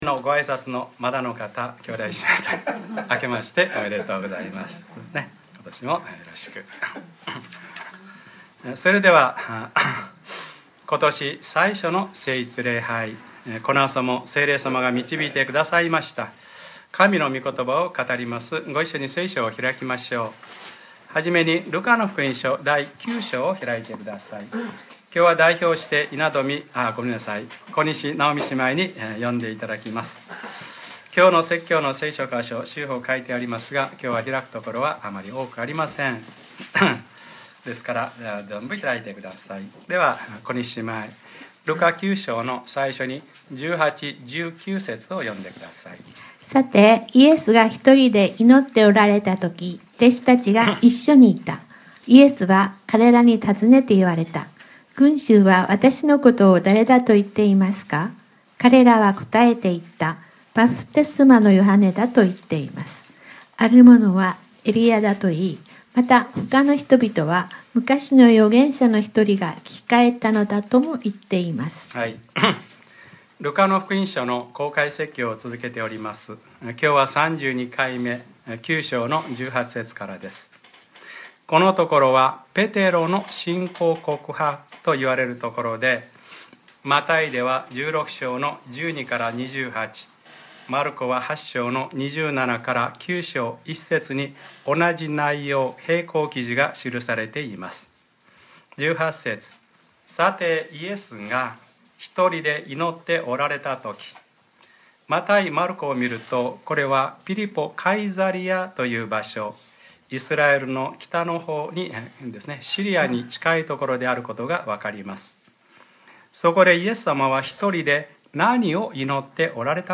2014年 1月 5日（日）礼拝説教『ルカ-３２：わたしをだれだと言いますか』